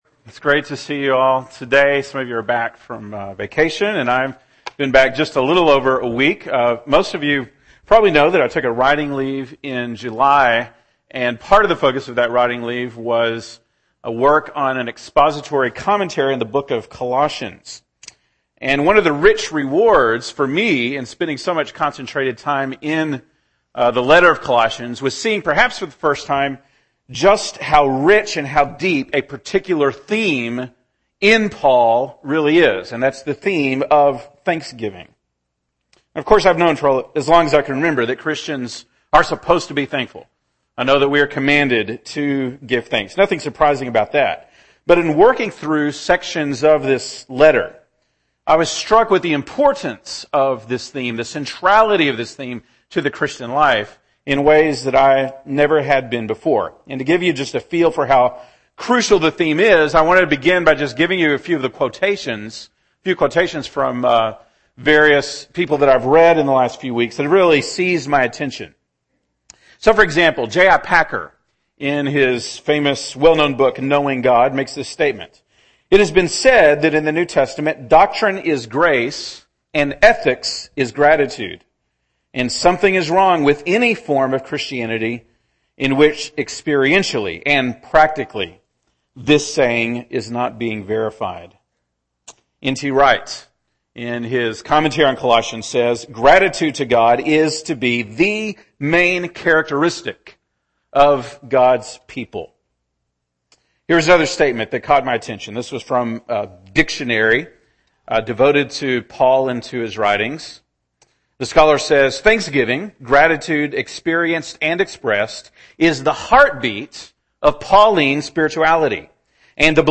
August 10, 2014 (Sunday Morning)